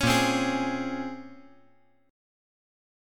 Bbm9 Chord
Listen to Bbm9 strummed